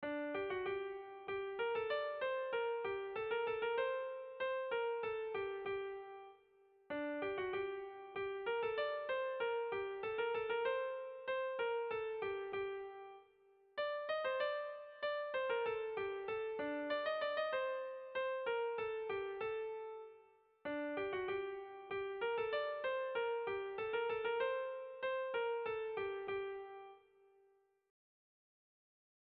Erromantzea
Zortziko handia (hg) / Lau puntuko handia (ip)
AABA